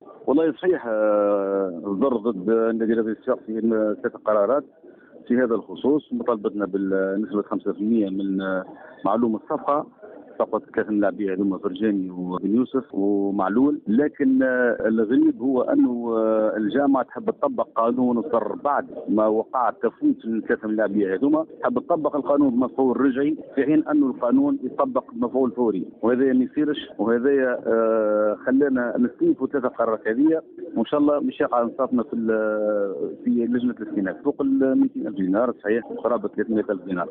في تصريح لمراسل جوهرة أف أم